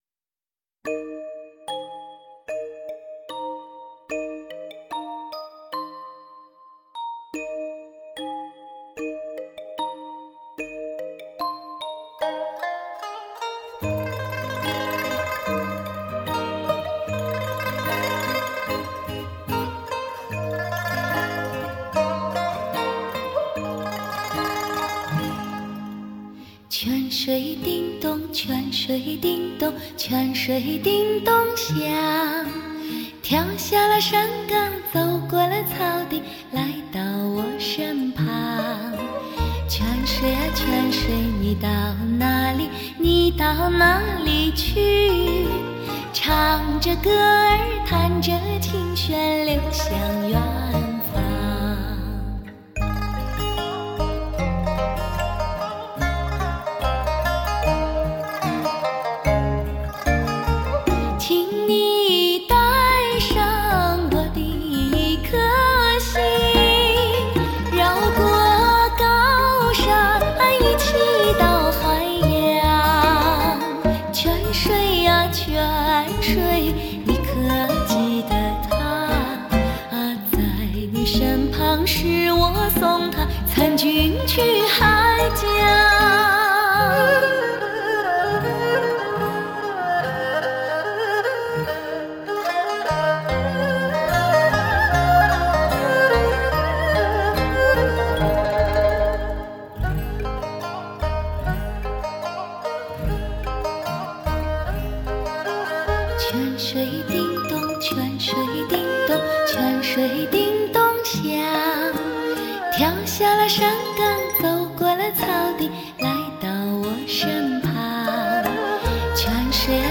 一样的经典民歌！